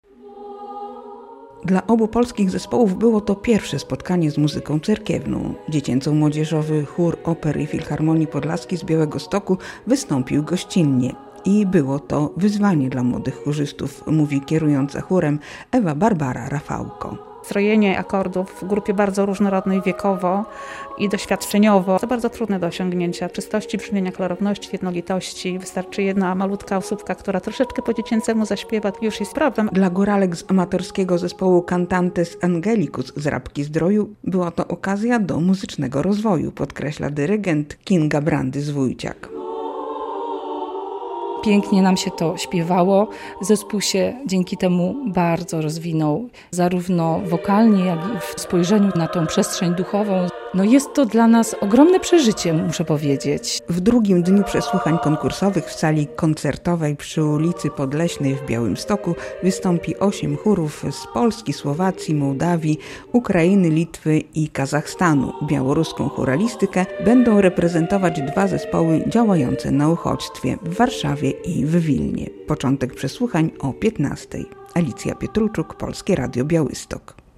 rozpoczęły się przesłuchania